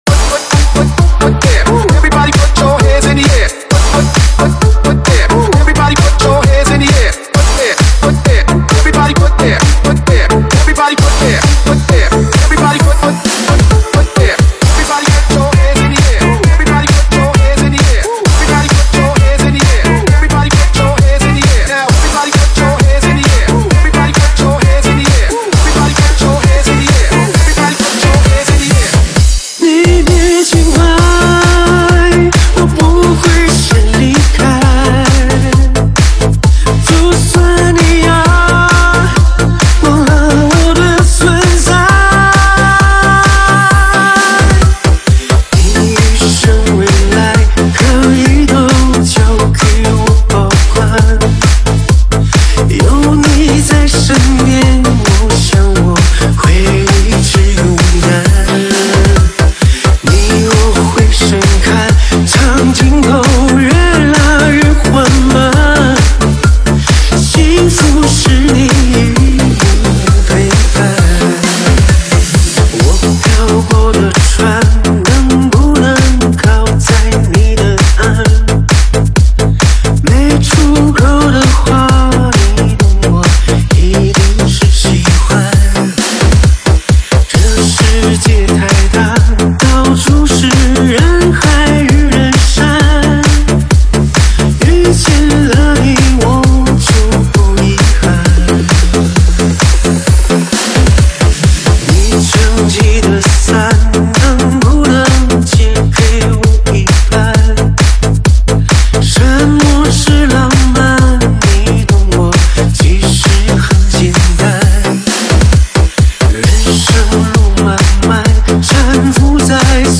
栏目： 现场串烧